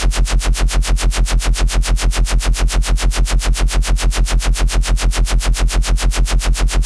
Tag: 140 bpm Dubstep Loops Bass Wobble Loops 1.15 MB wav Key : F